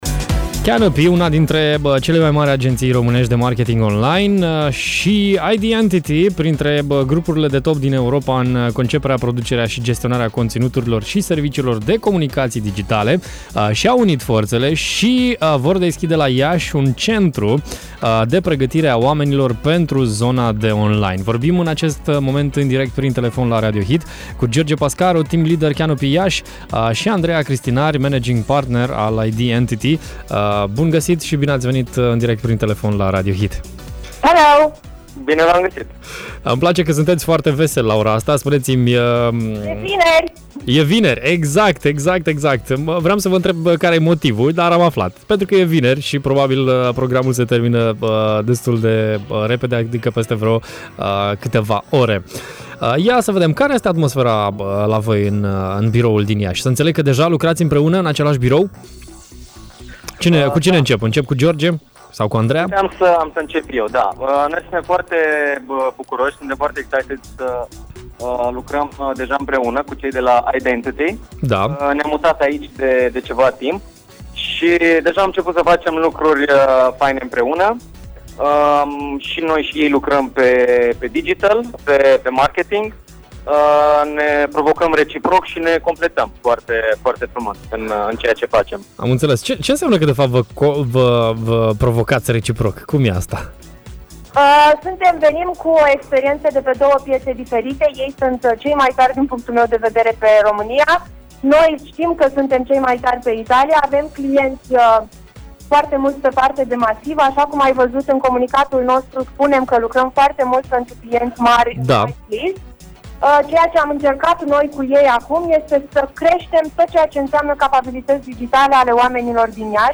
Mai multe detalii am aflat în direct la Radio Hit